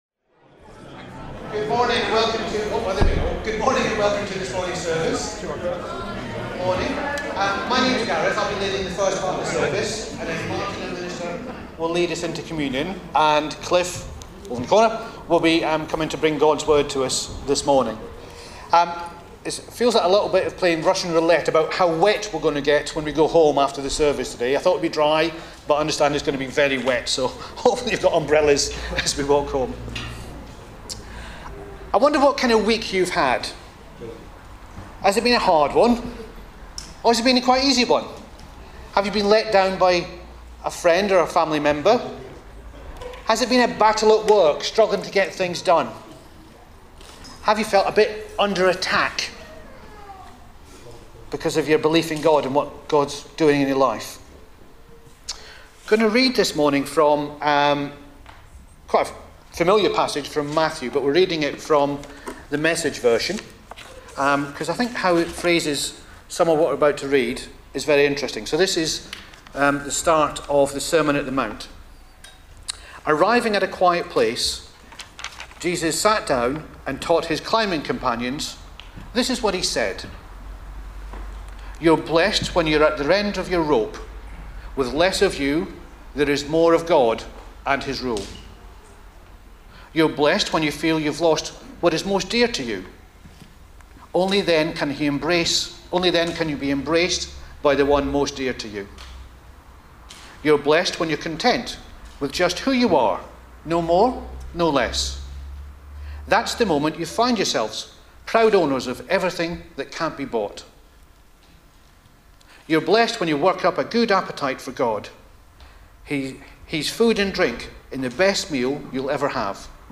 20 July 2025 – Morning Communion Service